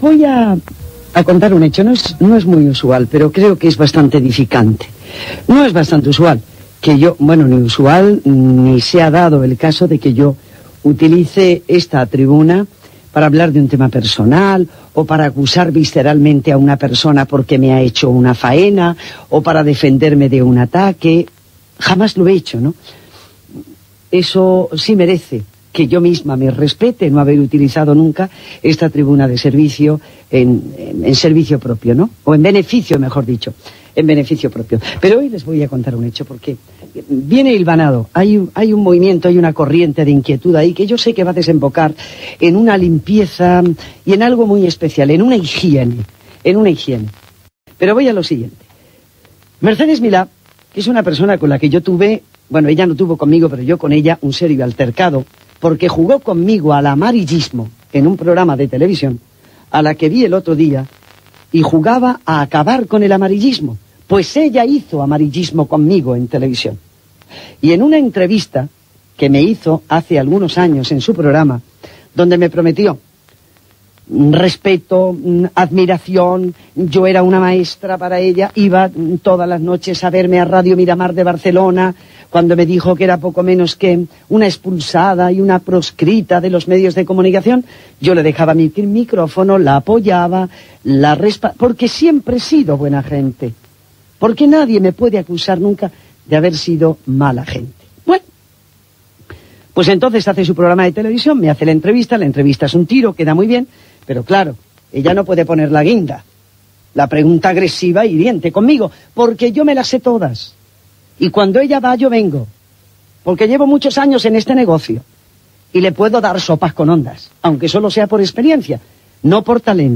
c04b580462ff1e89000f50a4df230b2bbd46cb96.mp3 Títol Cadena COPE Emissora COPE Miramar Cadena COPE Titularitat Privada estatal Nom programa Directamente Encarna Descripció Encarna Sánchez es mostra molesta amb la periodista Mercedes Milá.